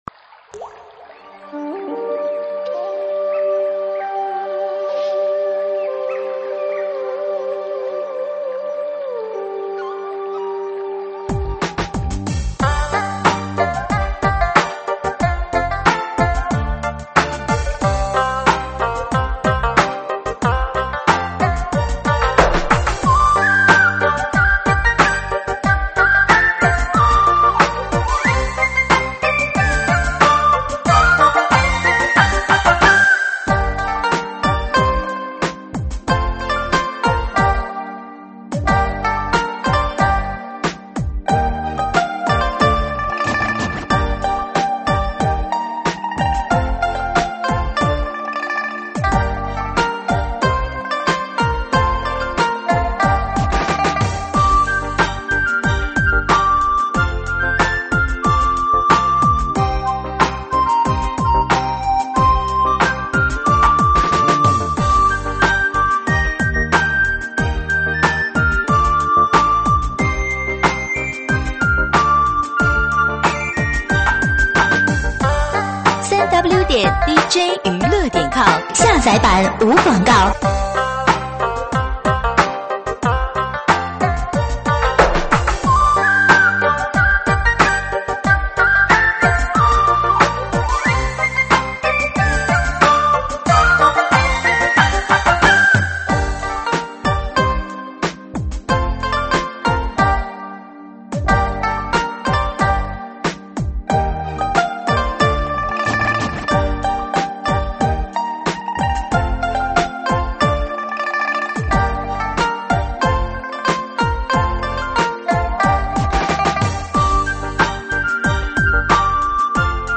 慢摇舞曲
舞曲类别：慢摇舞曲